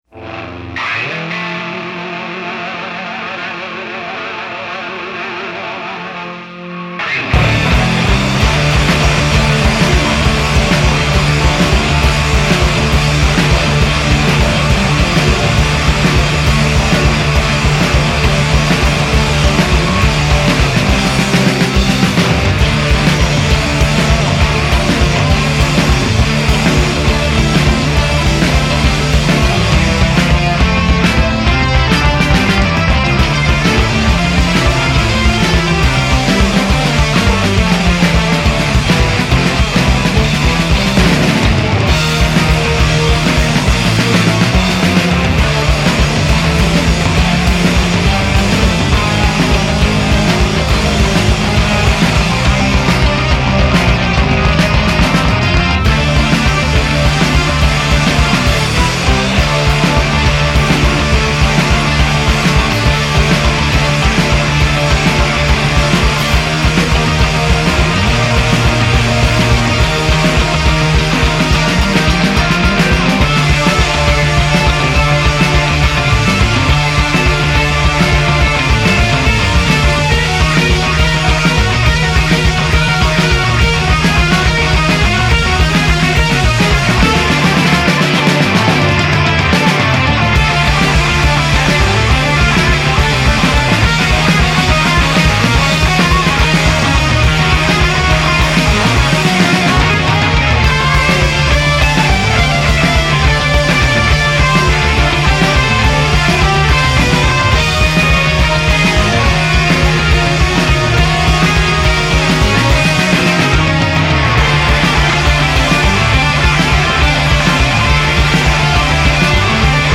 harder yet prettier